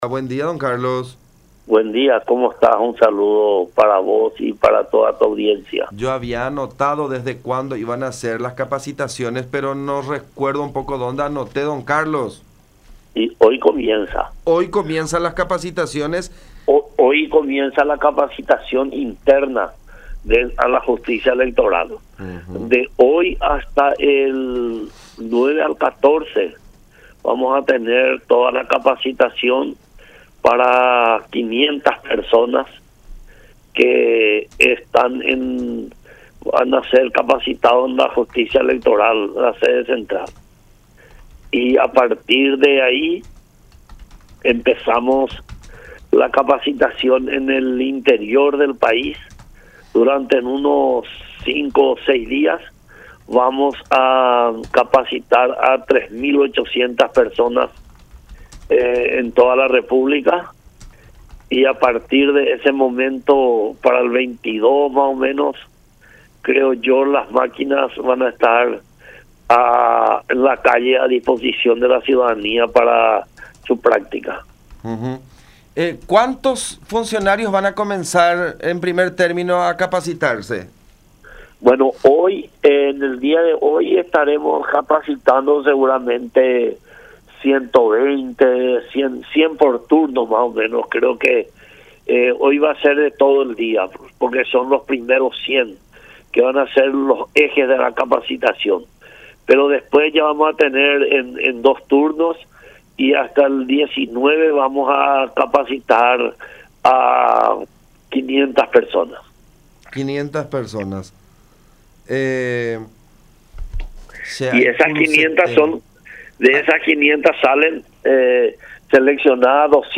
en diálogo con La Unión